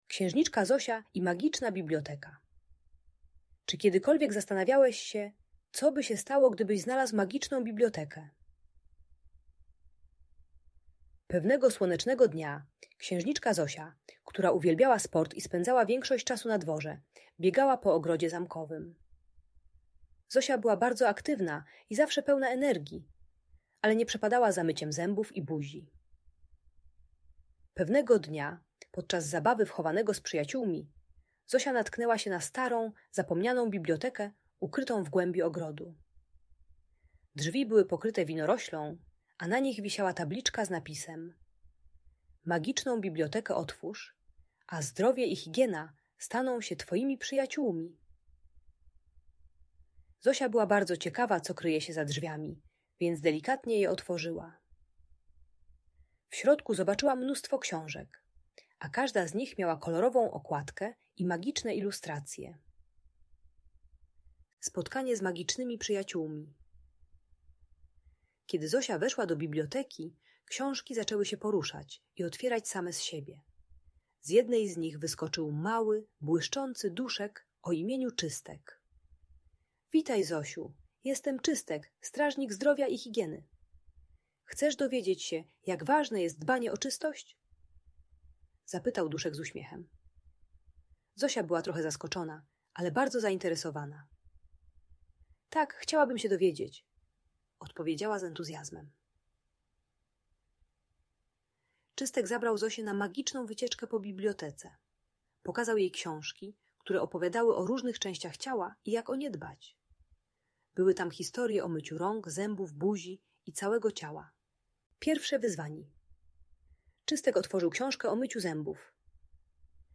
Księżniczka Zosia i Magiczna Biblioteka - Audiobajka